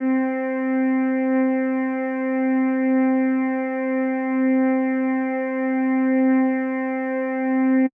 Synths
She Pad.wav